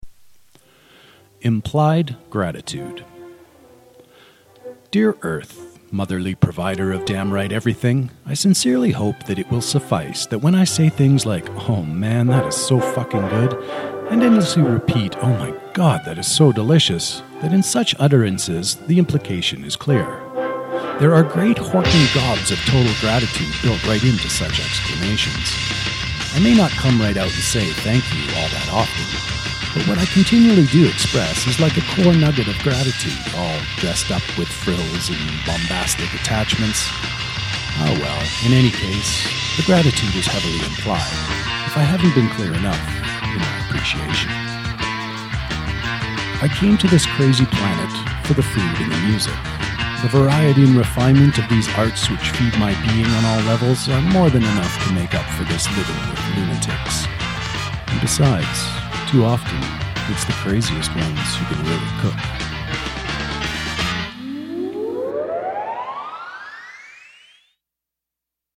(Author Narration with musical accompaniment: Marie Celeste excerpt by Sahara)